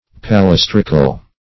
Search Result for " palestrical" : The Collaborative International Dictionary of English v.0.48: Palestrian \Pa*les"tri*an\, Palestric \Pa*les"tric\, Palestrical \Pa*les"tric*al\, a. [L. palaestricus, Gr.